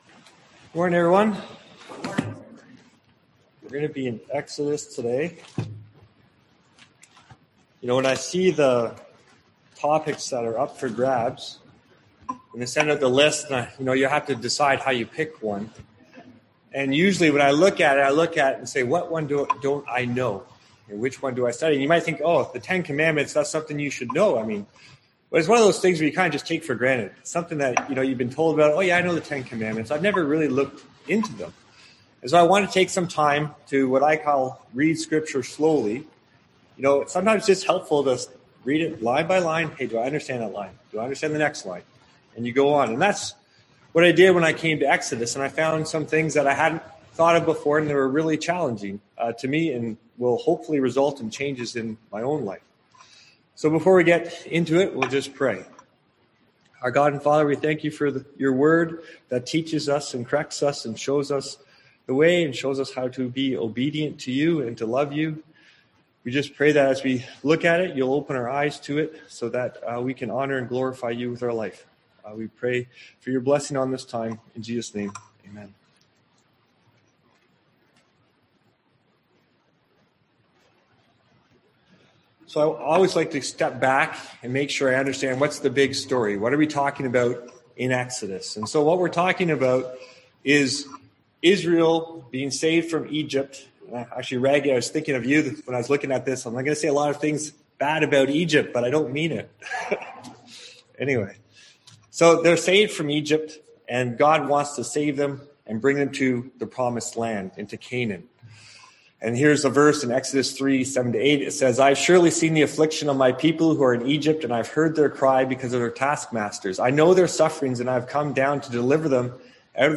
Passage: Exodus 20 Service Type: Sunday AM Topics: Law , Ten Commandments